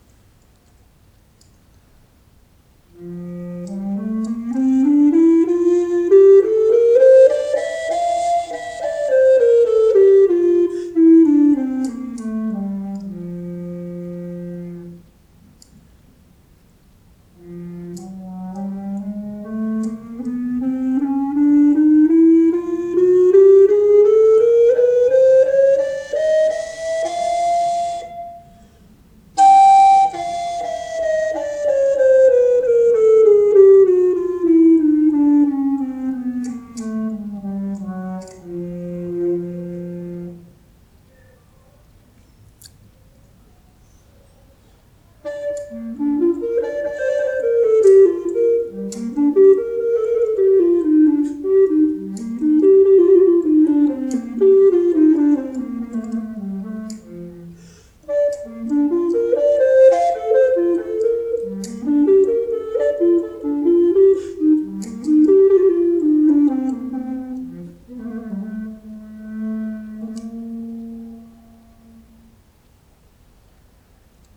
【材質】メープル
バスリコーダーは特に海外製のものの中には息を非常に多く吹き込まないといけないケースがありますが、タケヤマのバスリコーダーは概してそれほど大量の息を入れずとも鳴ってくれます。さらにこの個体はまず音程が良いのが嬉しい。また充実の低音域に加え、最高音域の反応も抜群でファルセットのように軽々と奏でられます。